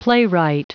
Prononciation du mot playwright en anglais (fichier audio)
Prononciation du mot : playwright